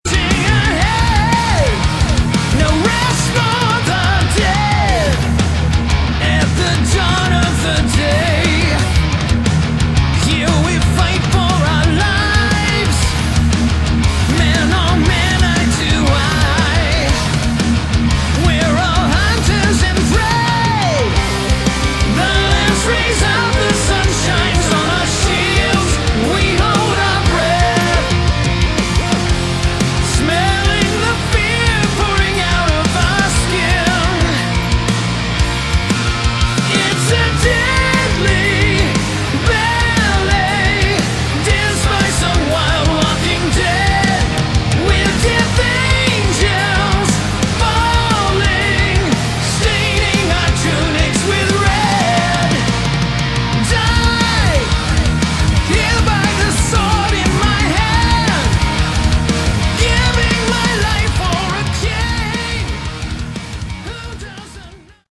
Category: Melodic Metal
vocals
guitars
keyboards & piano
bass
drums